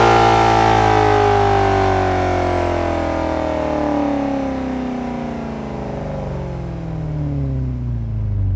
rr3-assets/files/.depot/audio/Vehicles/v8_11/v8_11_decel.wav
v8_11_decel.wav